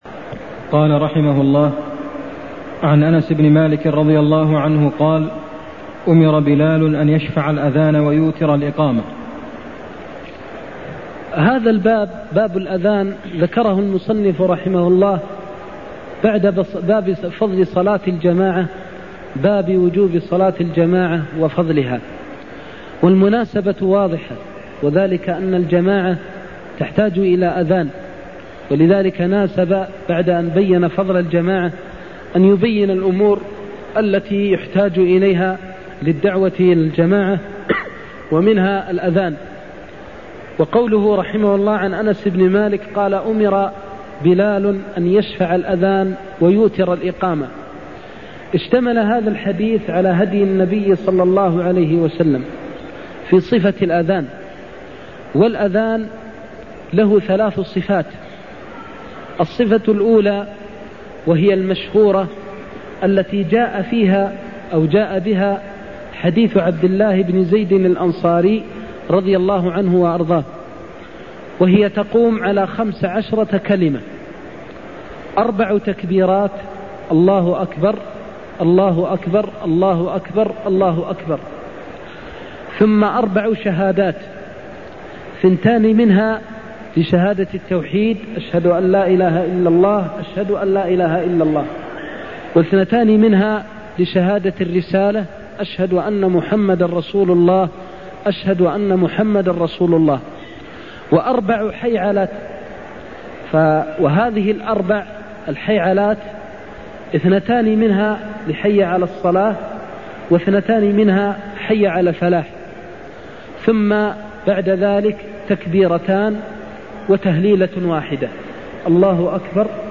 المكان: المسجد النبوي الشيخ: فضيلة الشيخ د. محمد بن محمد المختار فضيلة الشيخ د. محمد بن محمد المختار أمر بلال أن يشفع الأذان ويتر الإقامة (60)